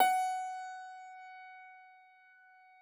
53j-pno16-F3.wav